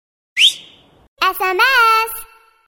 New_Sms_Tone_2009.mp3